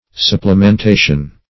Supplementation \Sup`ple*men*ta"tion\, n.